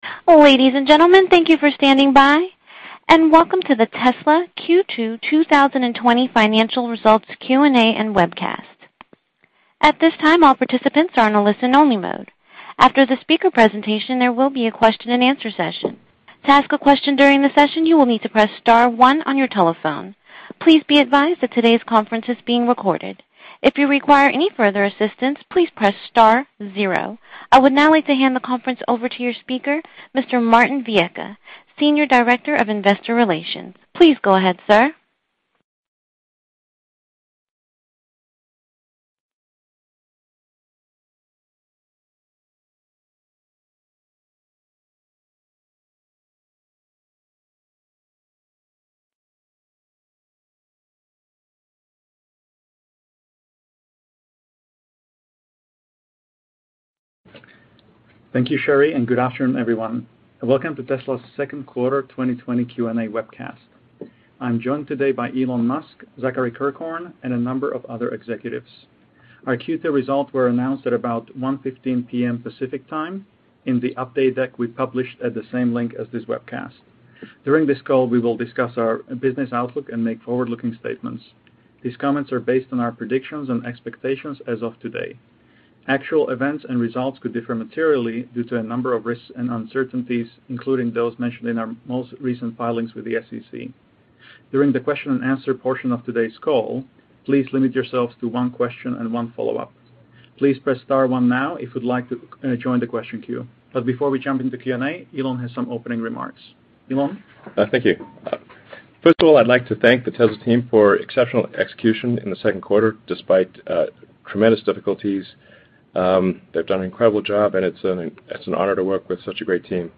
Tesla, Inc. Q2 2020 Financial Results and Q&A Webcast | Tesla Investor Relations
Tesla--Inc--Q2-2020-Financial-Results-and-Q-A-Webcast.mp3